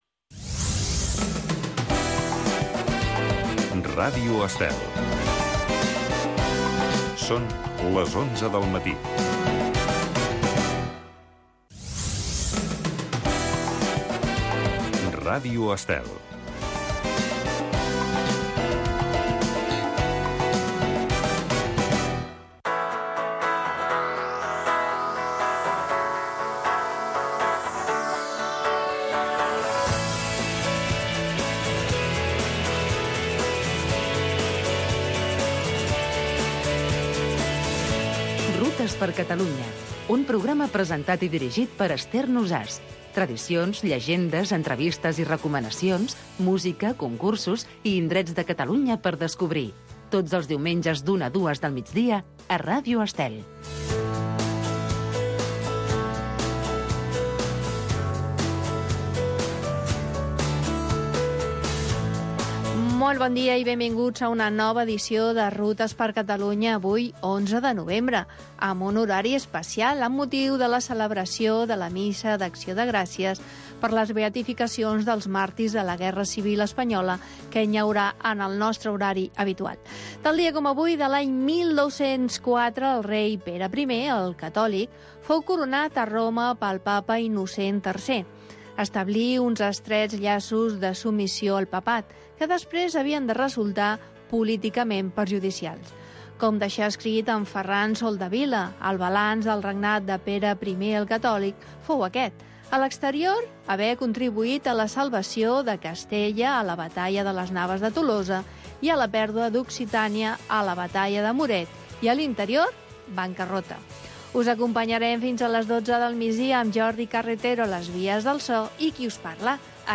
Rutes x Catalunya. Tradicions, festes, cultura, rutes, combinat amb entrevistes i concurs per guanyar molts premis.